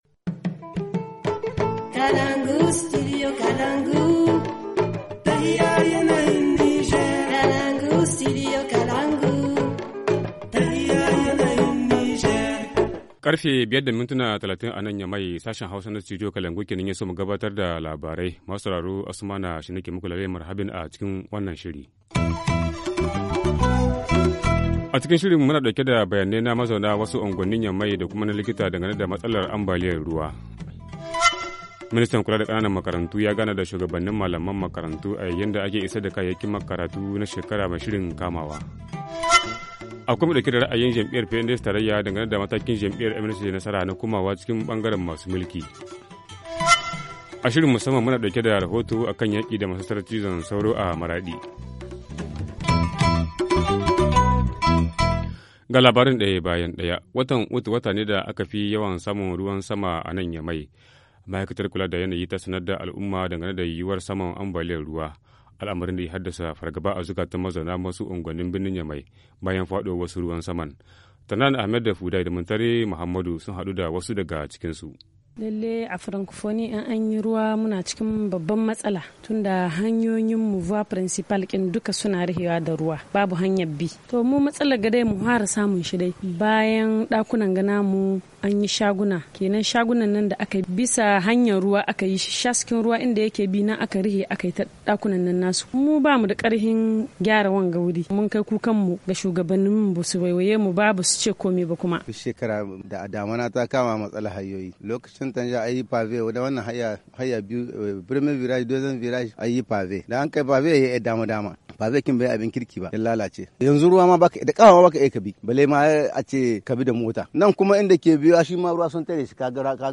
Journal du 16 août 2016 - Studio Kalangou - Au rythme du Niger